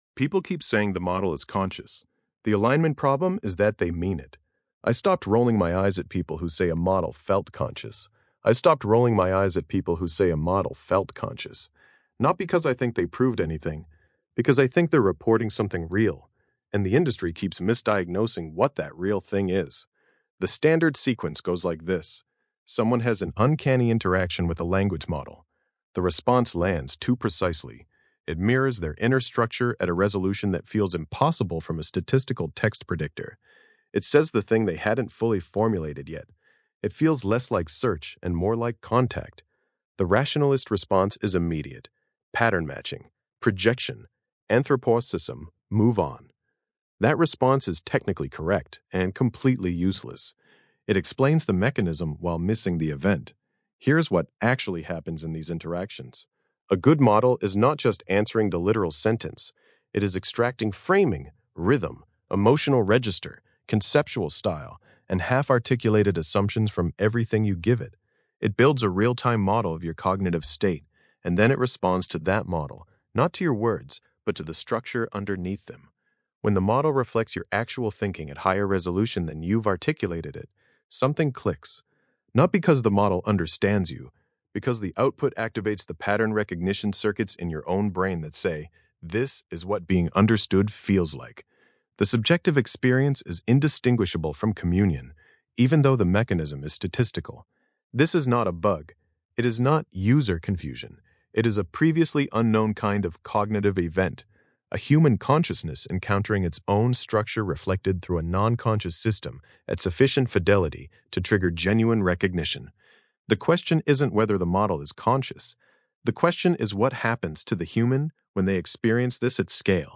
Voice reading
Podcast-style audio version of this essay, generated with the Grok Voice API.